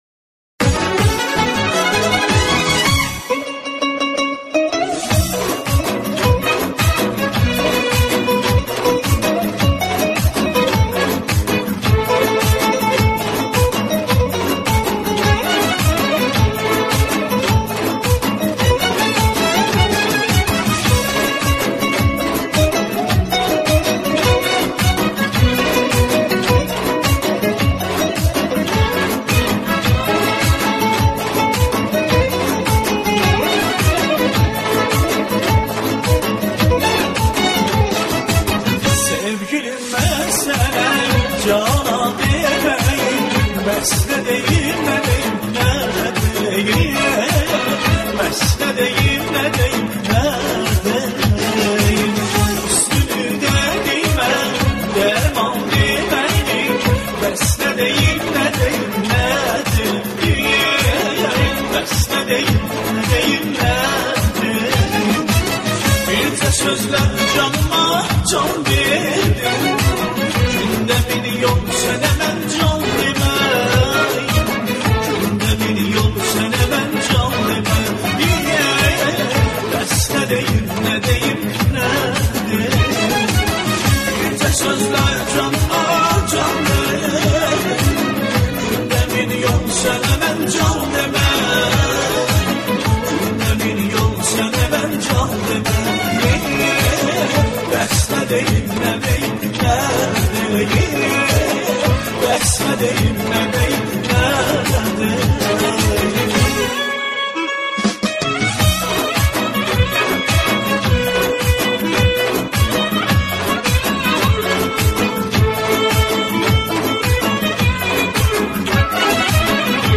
موسیقی آذری